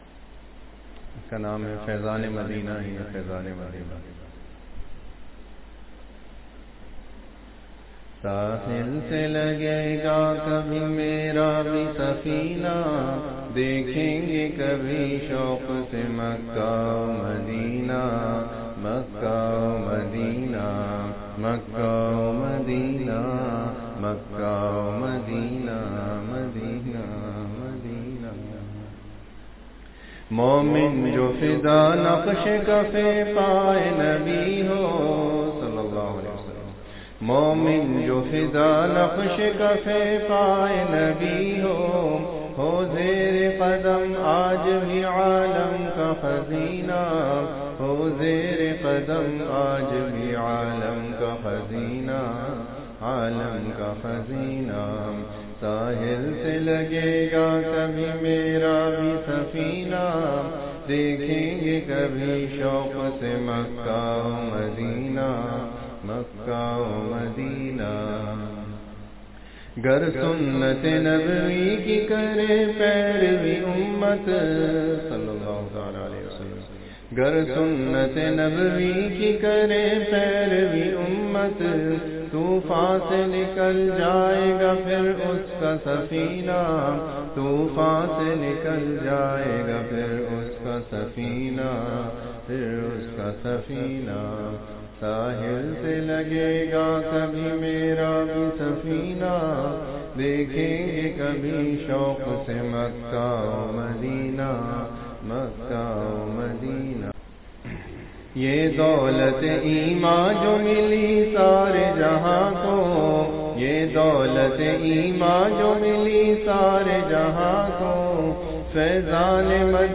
7.9.25 Itwar Majlis -(Naat Faizaan E Medina ,Khoone Tamanna Darde Dil ,Gunaah e Jaaria , dua Waseela